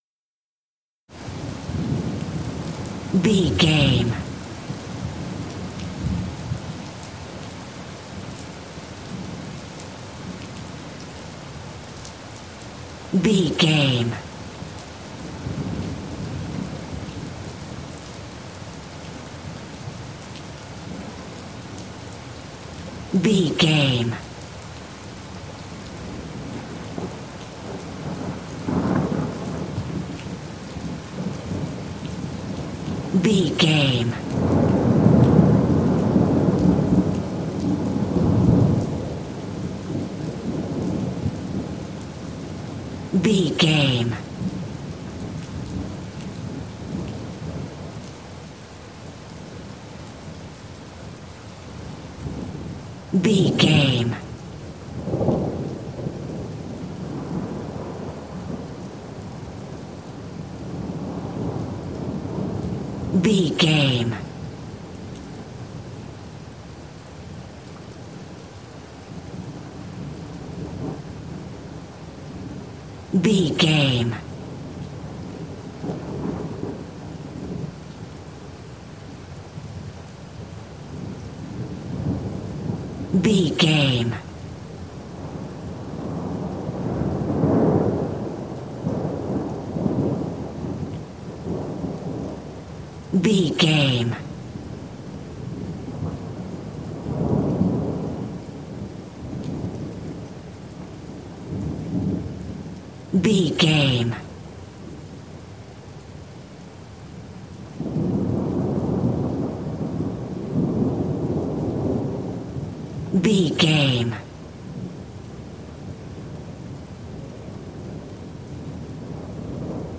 City rain small thunder 10
Sound Effects
nature
urban
ambience